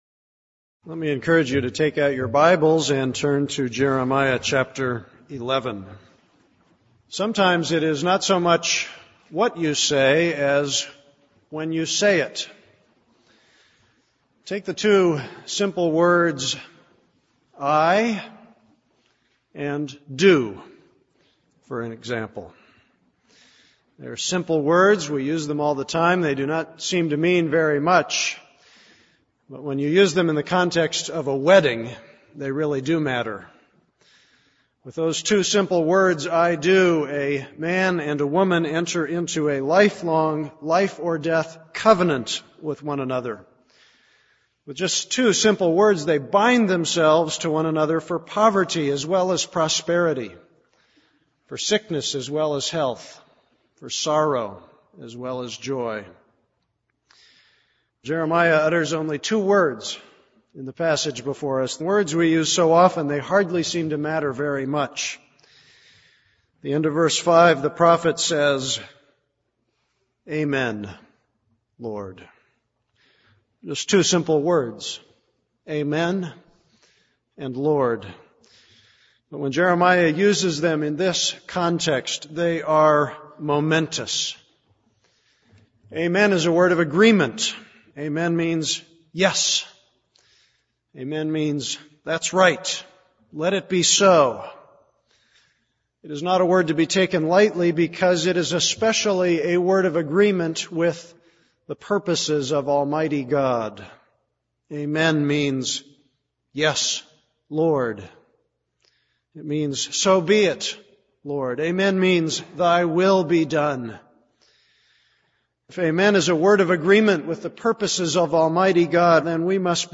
This is a sermon on Jeremiah 11:1-17.